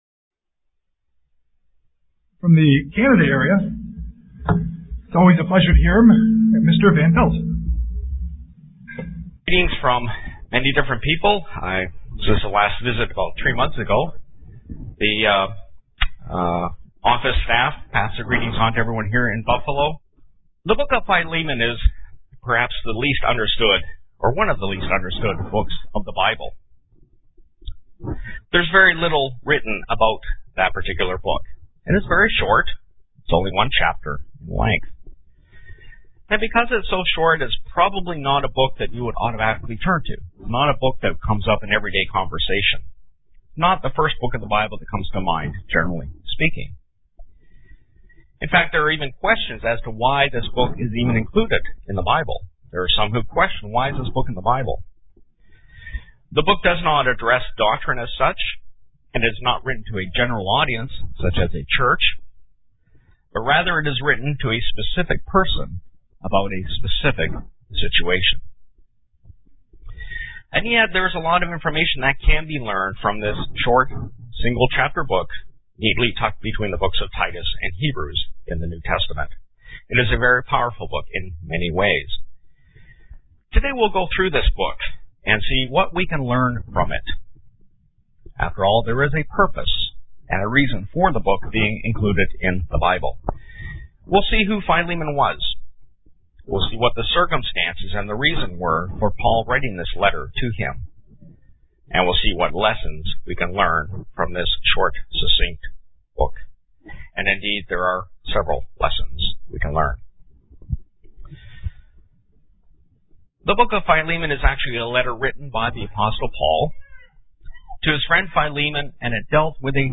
UCG Sermon Studying the bible?
Given in Buffalo, NY